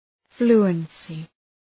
Προφορά
{‘flu:ənsı}